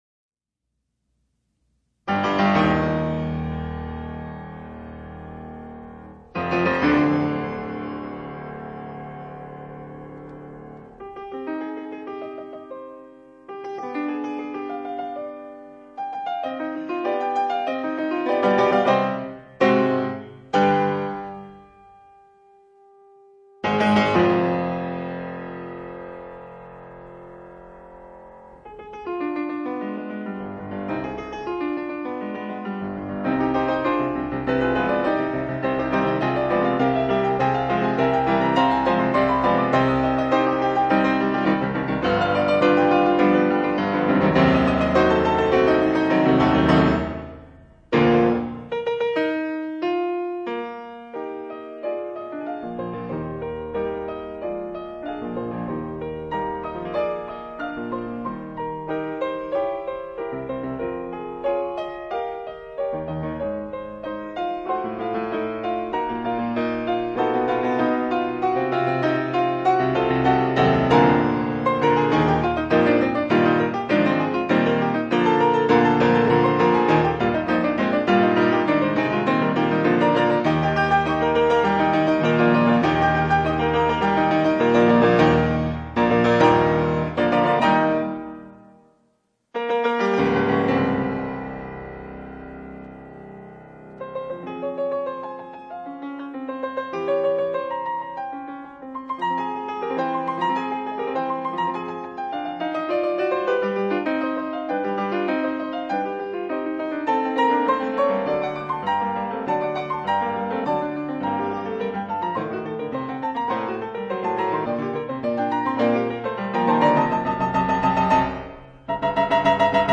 这套全集每张CD都用原版LP转录，并以LP的封面作为CD封面，非常具有收藏价值。